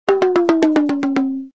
38_looseSound.mp3